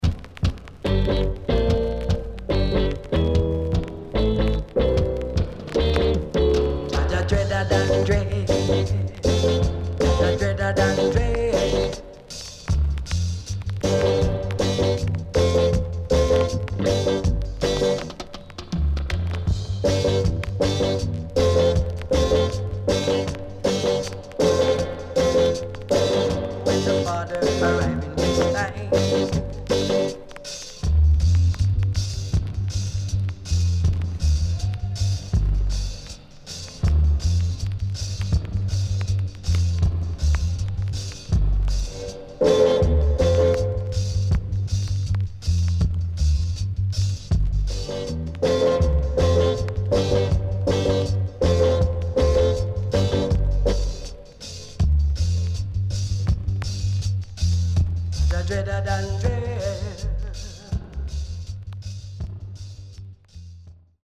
HOME > REGGAE / ROOTS  >  FUNKY REGGAE  >  RECOMMEND 70's
Good Funky Reggae & Dubwise
SIDE A:所々チリノイズがあり、少しプチノイズ入ります。